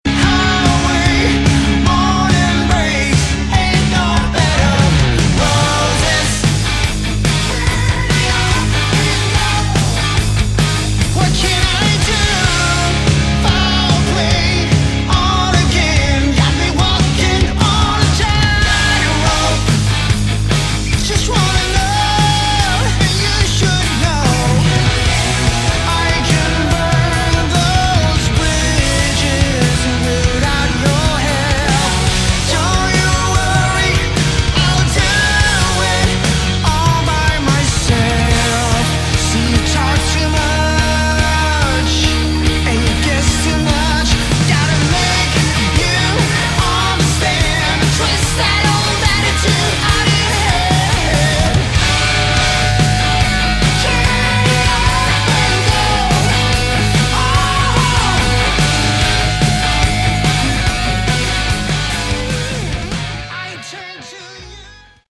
Category: Melodic Hard Rock
guitars
bass, vocals
drums